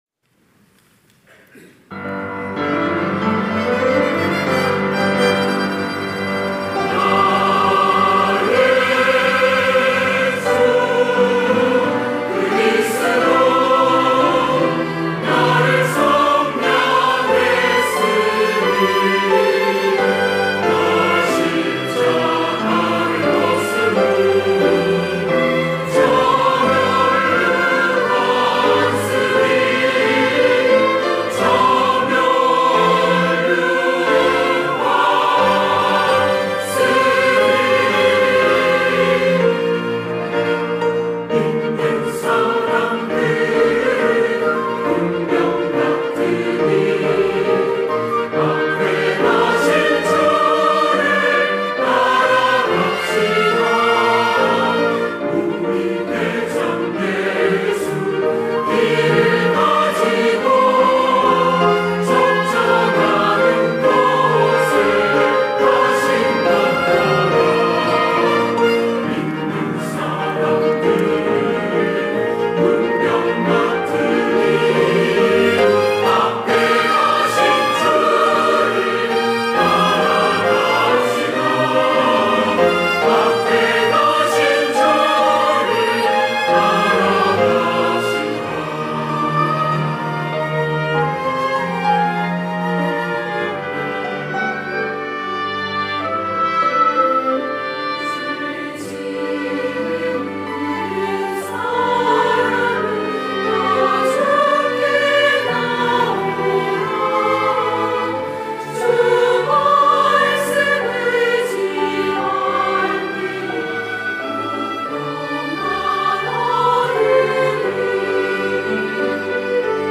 할렐루야(주일2부) - 믿는 사람들은 군병 같으니
찬양대